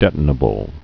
(dĕtn-ə-bəl)